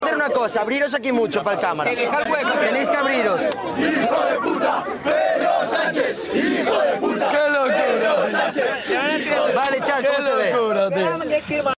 decenas de seguidores recordaban en directo y se unen asi, a lo que ya es una tendencia en cual sitio de España y es el cántico contra el presidente.